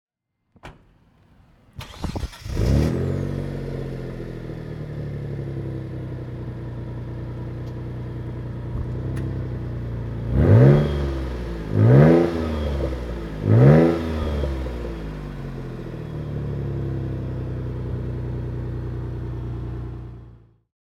To give you an impression of the variety, we have selected ten different engine sounds.
Mitsubishi 3000 GT (1996) - Starting and idling